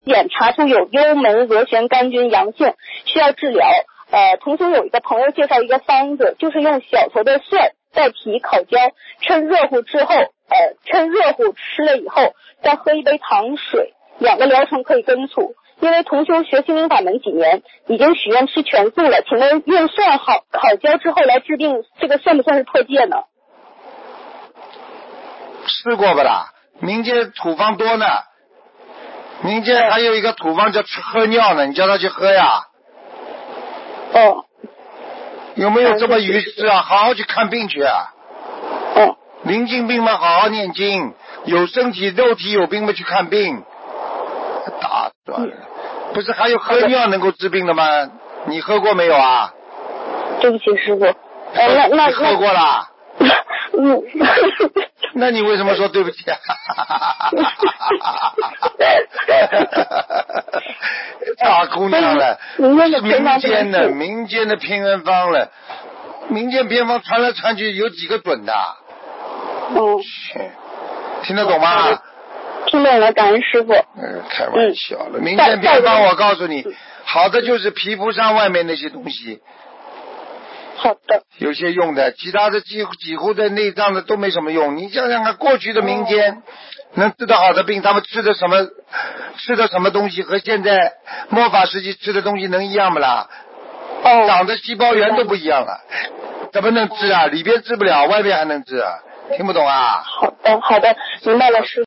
女听众:请问师父，有一种方法叫“灌肠”，灌肠排毒，会不会导致漏掉自己身上念经的气场啊？